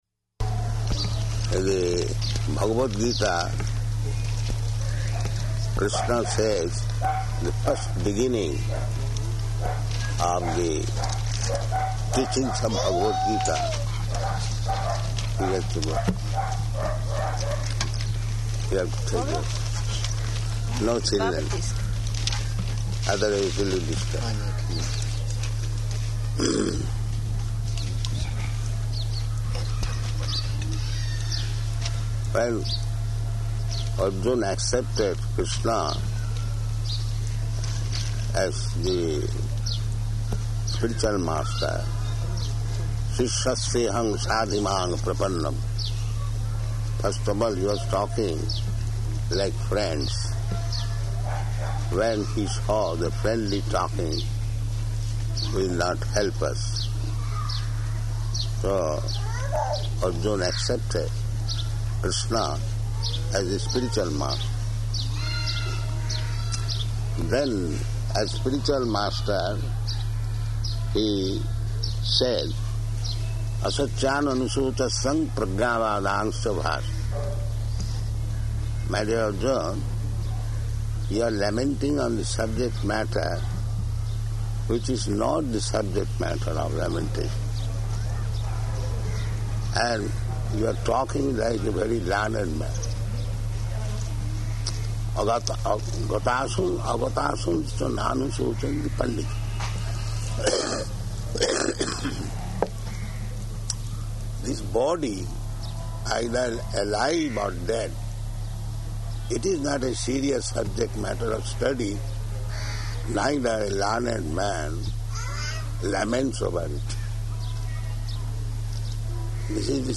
-- Type: Interview Dated: August 17th 1976 Location: Hyderabad Audio file